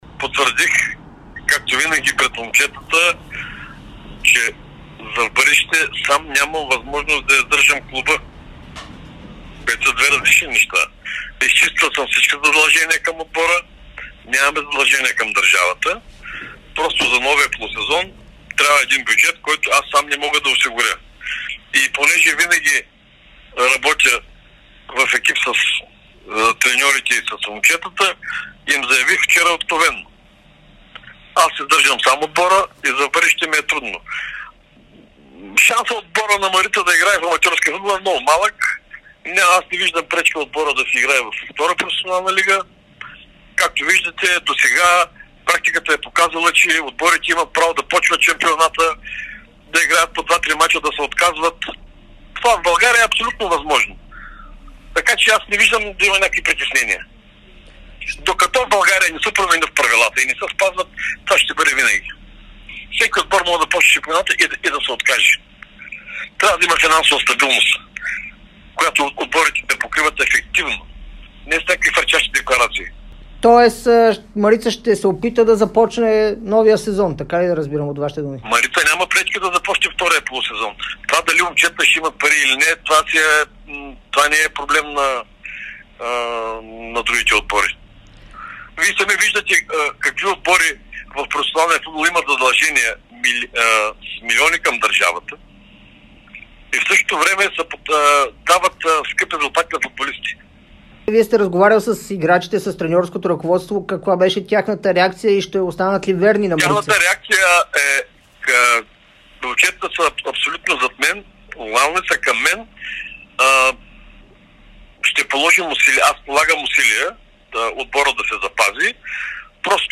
ексклузивно интервю